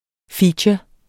Udtale [ ˈfiːtjʌ ]